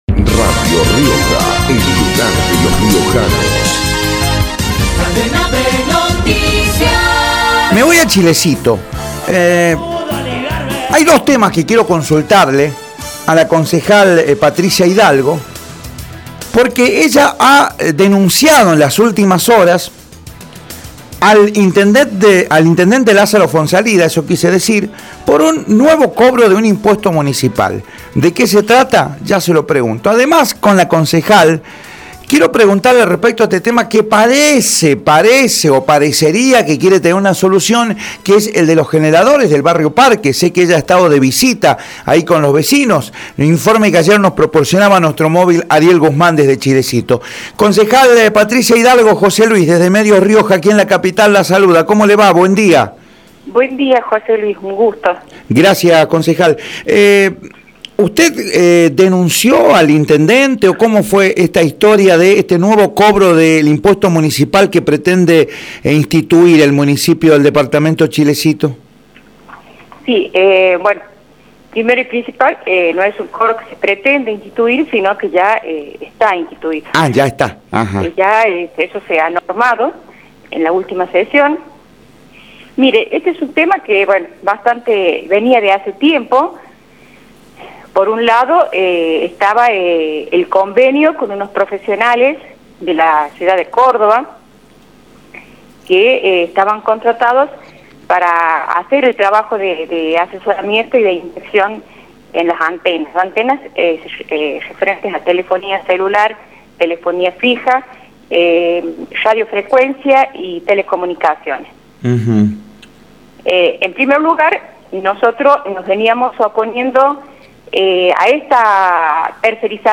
Ricardo Quintela, intendente, por Radio Rioja
patricia-hidalgo-concejal-de-chilecito-por-radio-rioja.mp3